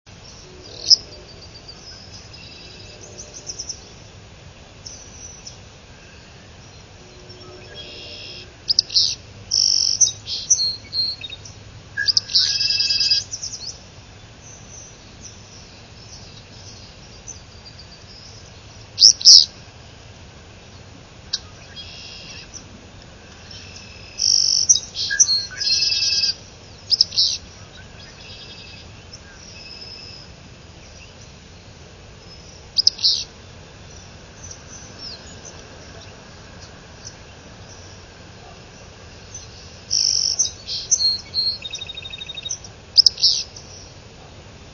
Willow Flycatcher
Aberdeen, 5/19/03, (175kb), with Yellow Warbler, Red-winged Blackbird and Song Sparrow in background.  Only the first song sequence is introduced by the classic "drrrriipp".
Again the bird waits for other birds to be silent.  The pause between sequences is variable.
flycatcher_willow_773.wav